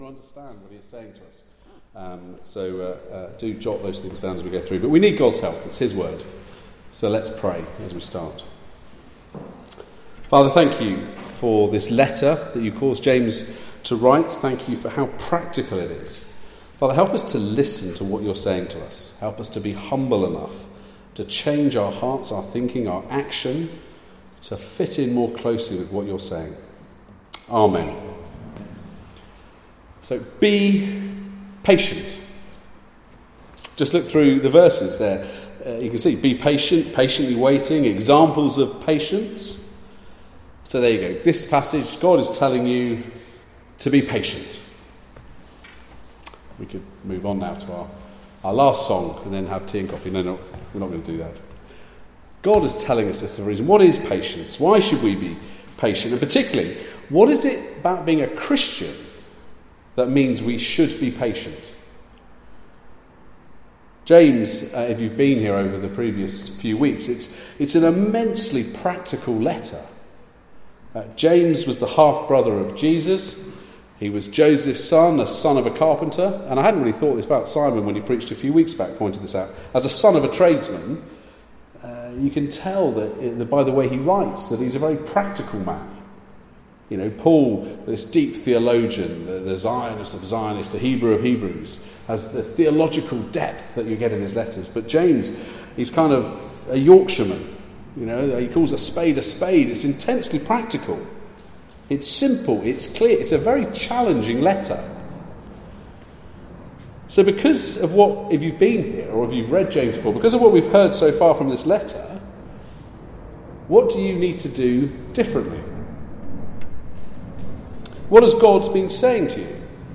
Passage: James 5: 7-12 Service Type: Weekly Service at 4pm Bible Text